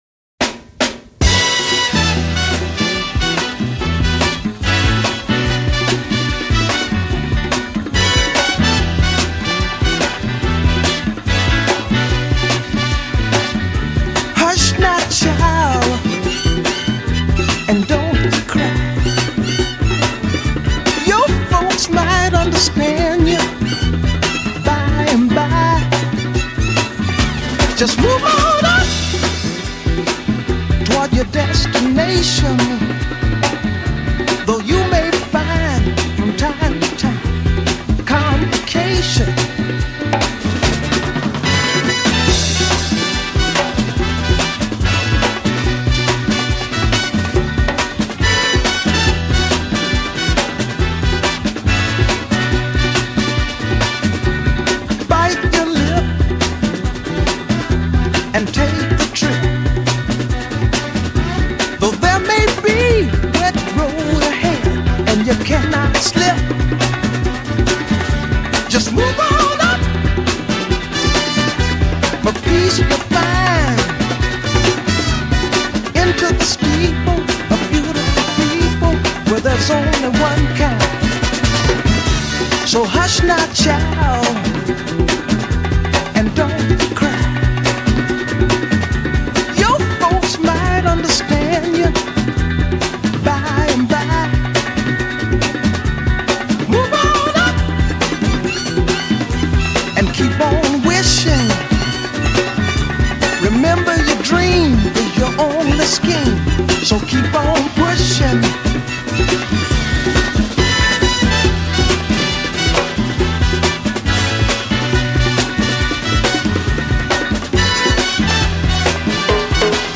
Theme: Flute, Couplet Voix, Refrain avec choeurs
Contrechant   violon :